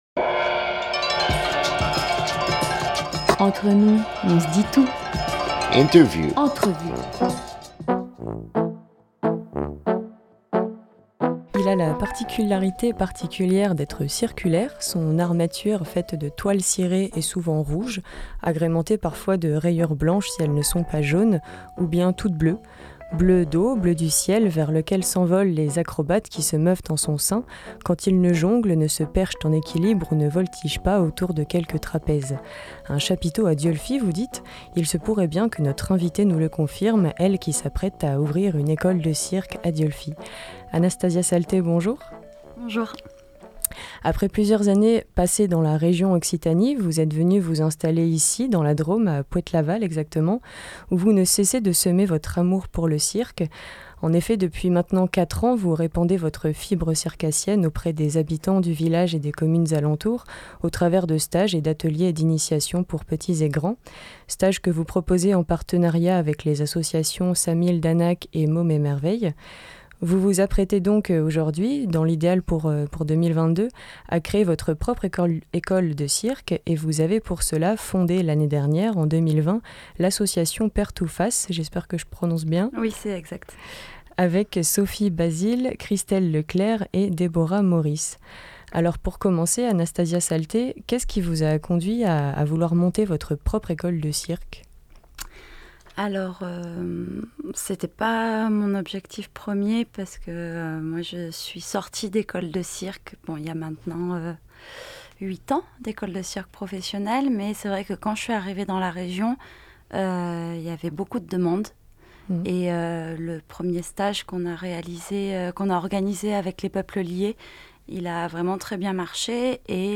5 juillet 2021 8:00 | Interview